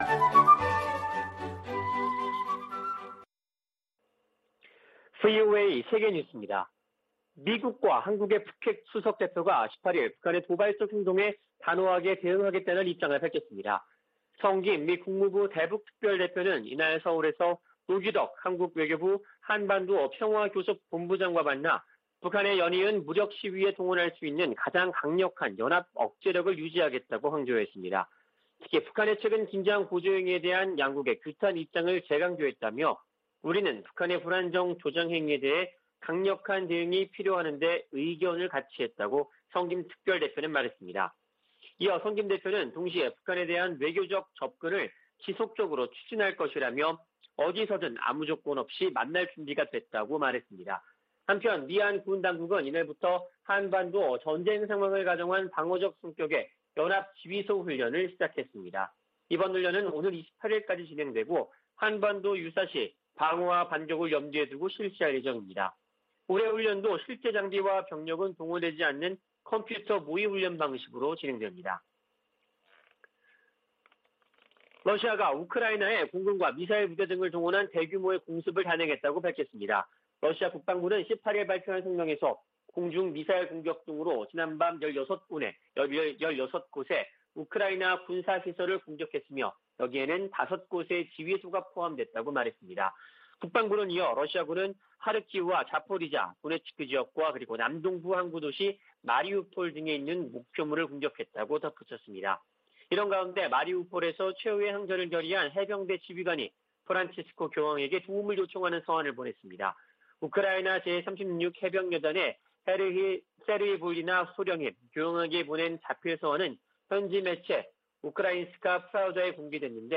VOA 한국어 아침 뉴스 프로그램 '워싱턴 뉴스 광장' 2022년 4월 19일 방송입니다. 북한 대외 관영 매체들은 김정은 국무위원장 참관 아래 신형 전술유도무기 시험발사가 성공했다고 보도했습니다. 미국령 괌 당국은 북한이 미상의 발사체를 쏜데 따라 역내 상황을 주시 중이라고 발표했다가 5시간 만에 철회했습니다. 미 국무부는 국제사회가 북한의 제재 회피를 막기위해 광범위한 협력을 하고 있으며 특히 대량살상무기관련 밀수 단속에 집중하고 있다고 밝혔습니다.